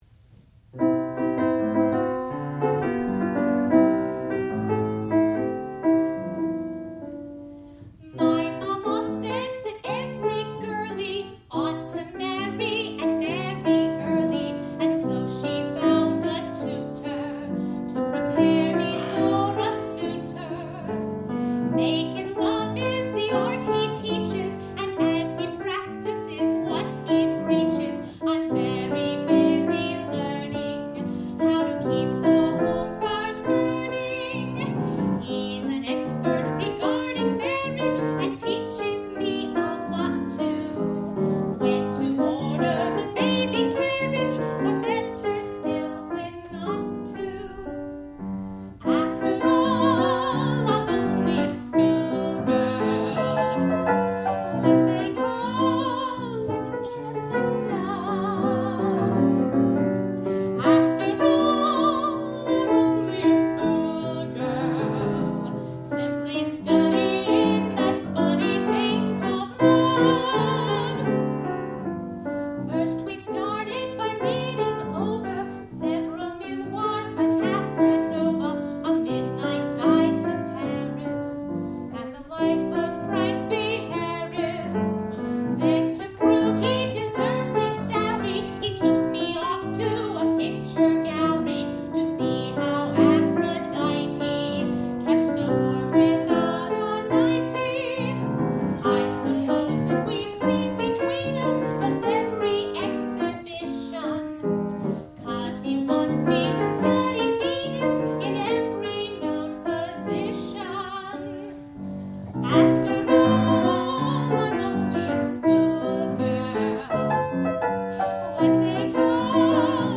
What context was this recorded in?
Qualifying Recital